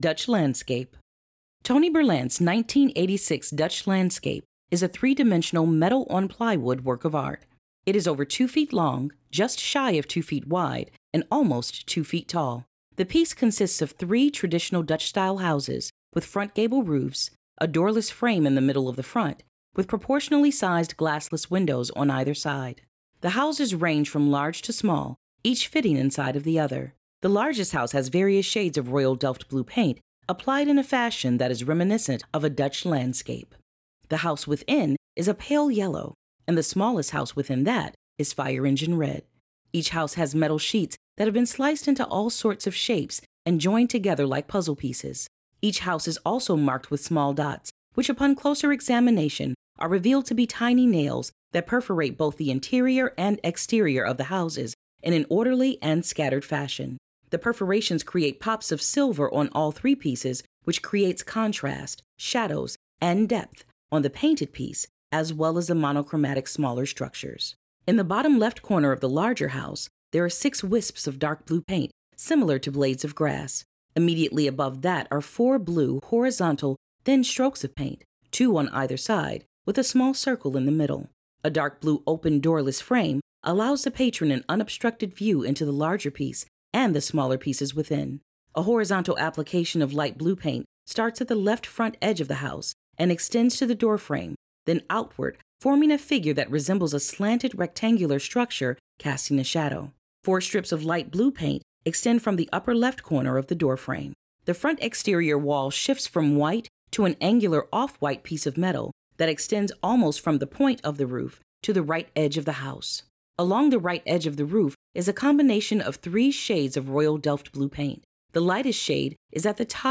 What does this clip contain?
Audio Description (03:15)